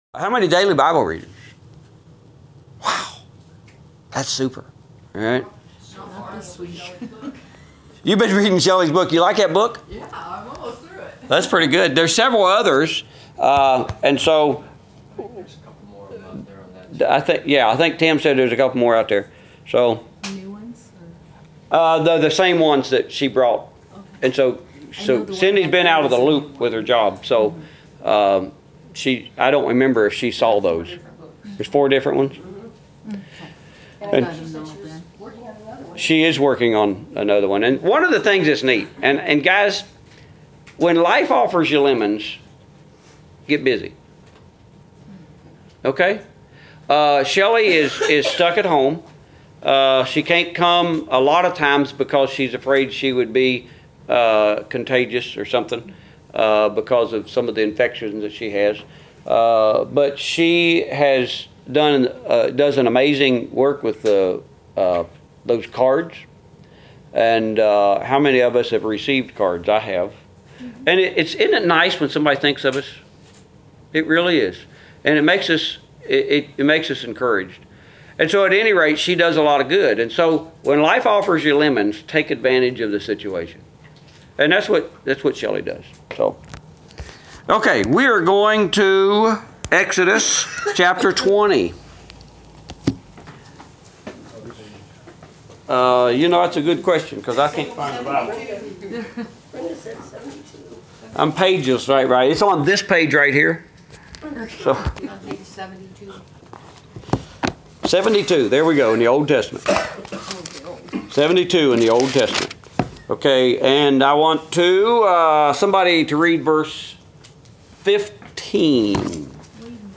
Adult Bible Class: 11/8/17